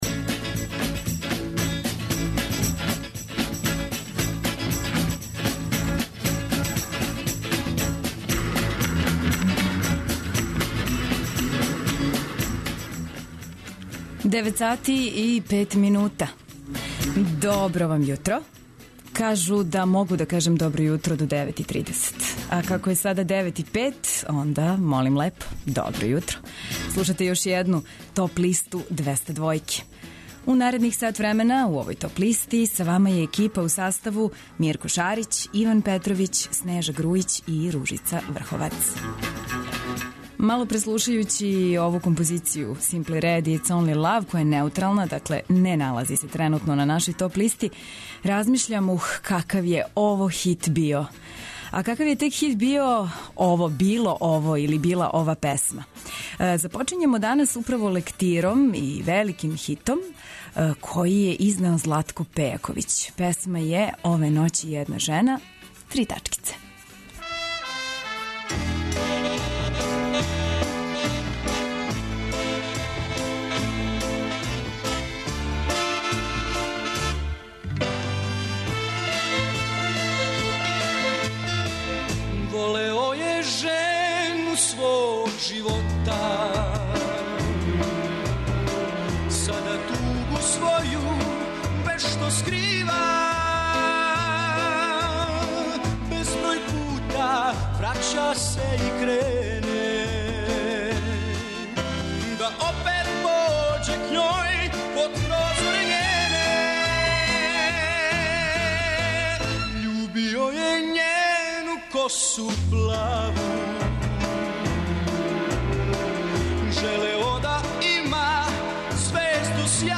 преузми : 24.39 MB Топ листа Autor: Београд 202 Емисија садржи више различитих жанровских подлиста.